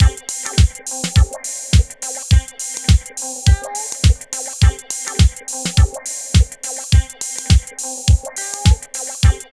136 LOOP  -R.wav